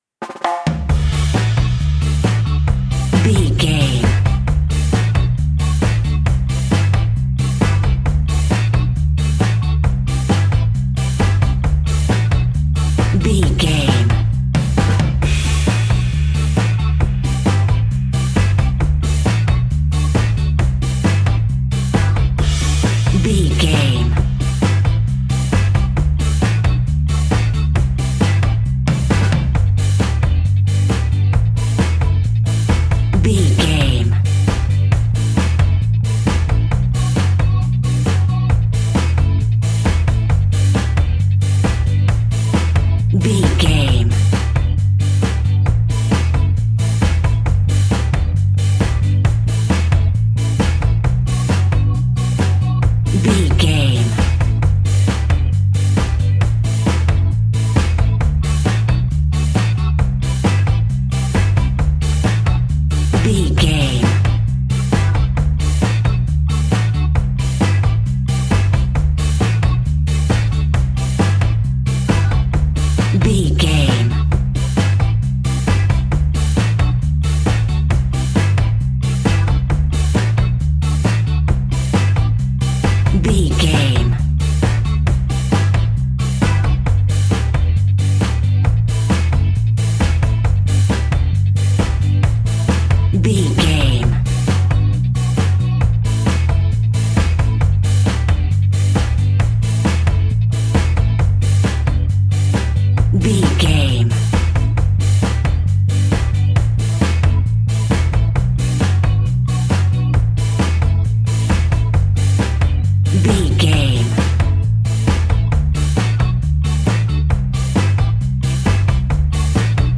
Classic reggae music with that skank bounce reggae feeling.
Aeolian/Minor
reggae
laid back
chilled
off beat
drums
skank guitar
hammond organ
percussion
horns